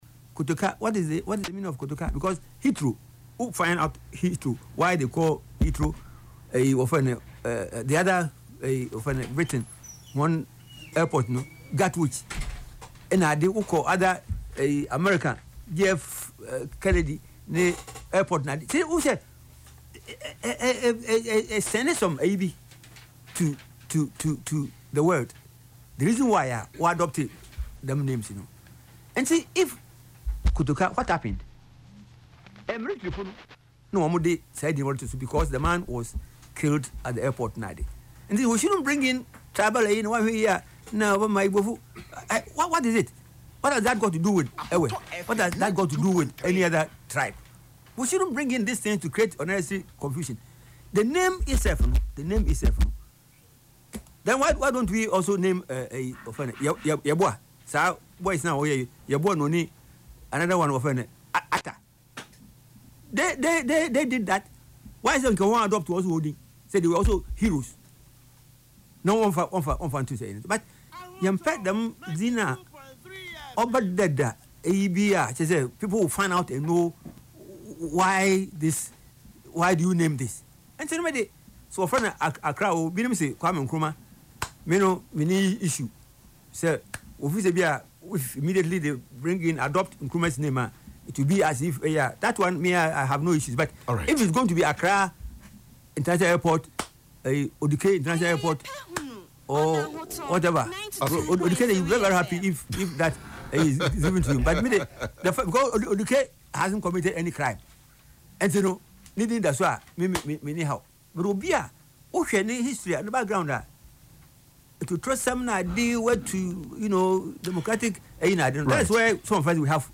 Speaking on Ahotor FM’s Yepe Ahunu programme on Saturday, February 7